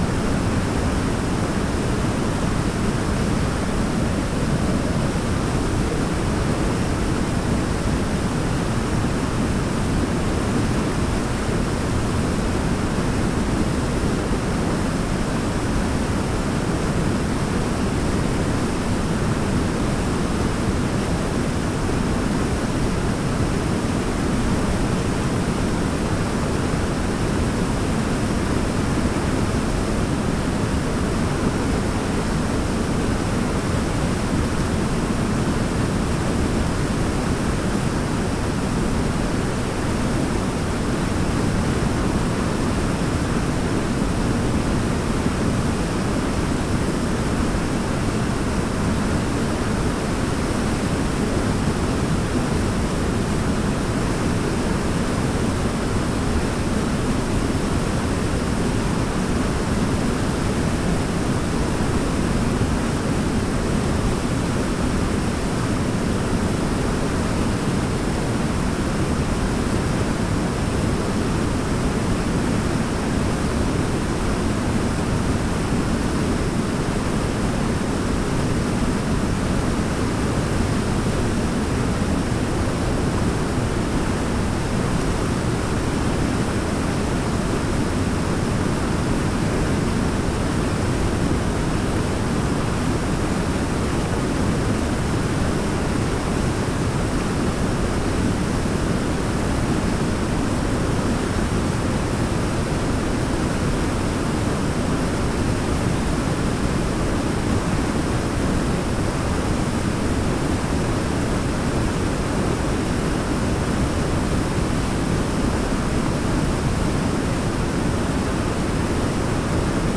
waterfall-1.ogg